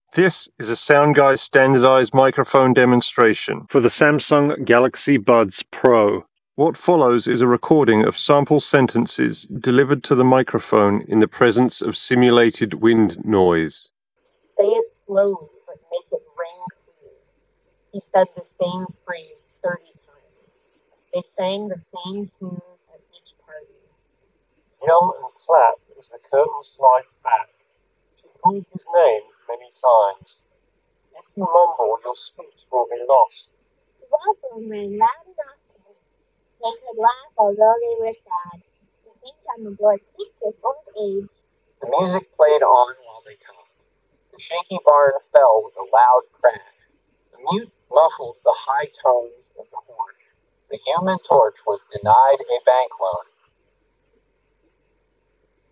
Samsung-Galaxy-Buds-Pro_Wind-microphone-sample.mp3